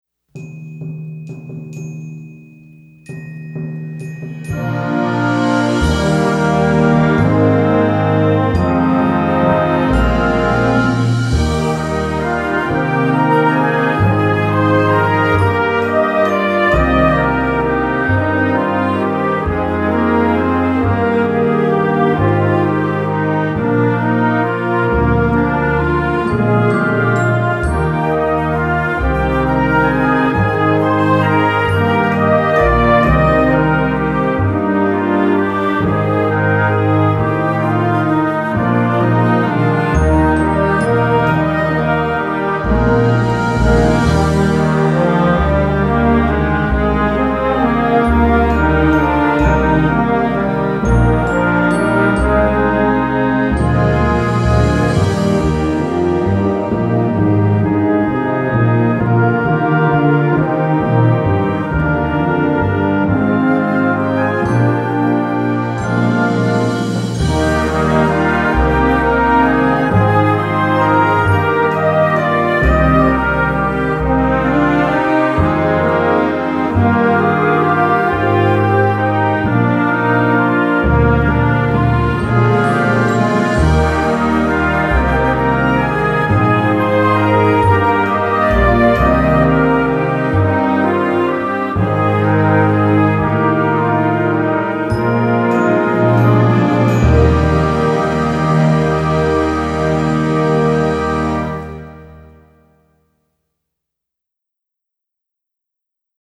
easy arrangement
Treated in an easy-to-grasp 3/4 time signature